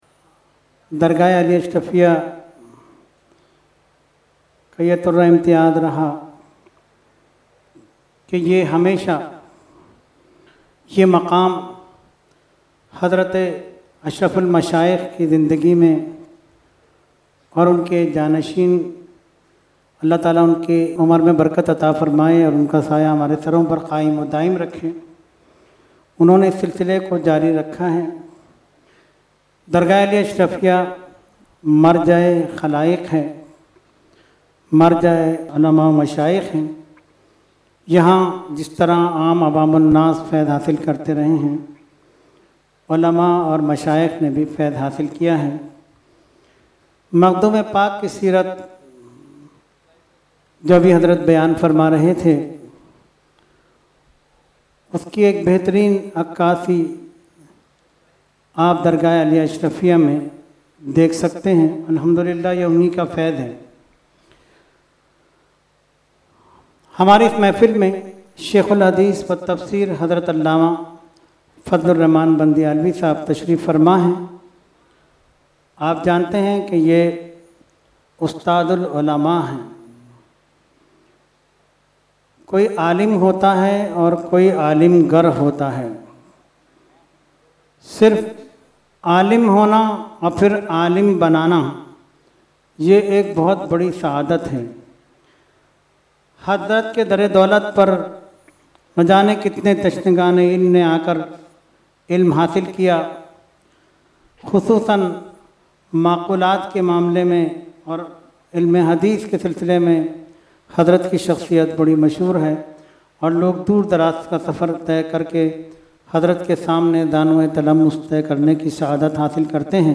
held on 25,26,27 August 2022 at Dargah Alia Ashrafia Ashrafabad Firdous Colony Gulbahar Karachi.